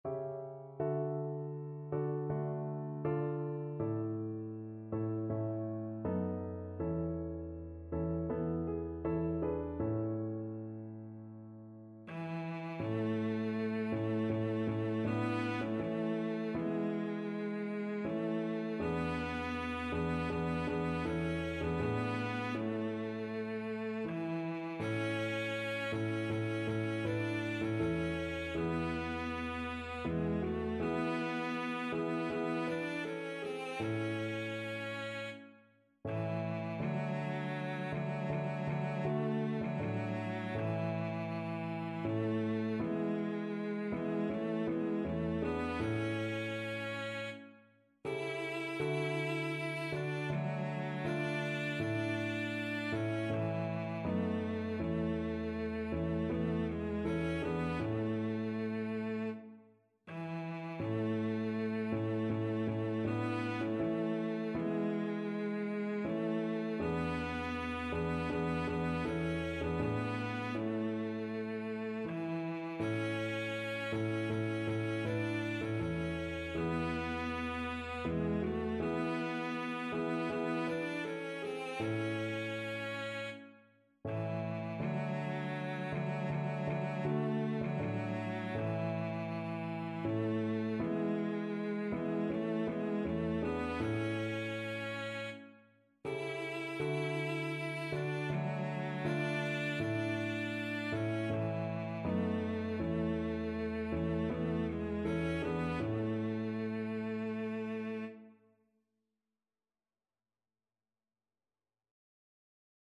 Traditional Trad. Myfanwy Cello version
Cello
A major (Sounding Pitch) (View more A major Music for Cello )
Andante espressivo
4/4 (View more 4/4 Music)
Traditional (View more Traditional Cello Music)